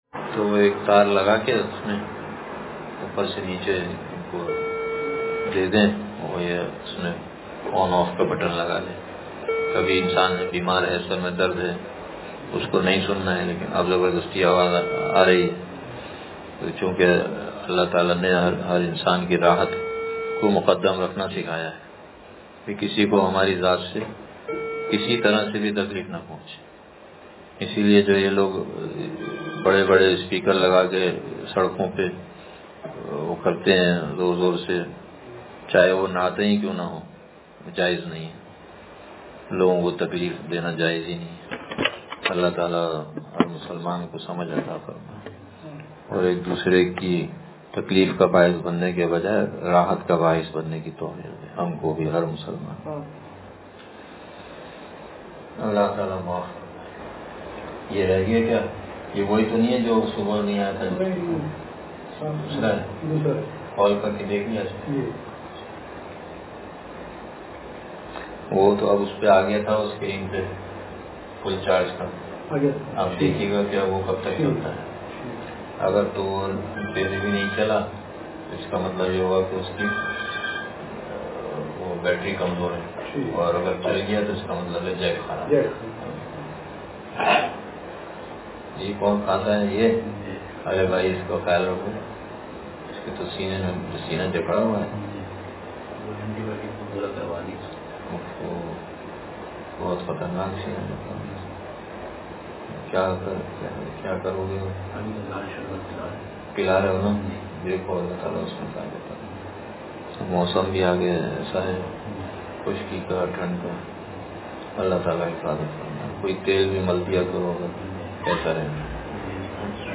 وہ دل جو تیری خاطر فریاد کر رہا ہے – ختم قرآن کے موقع پر بیان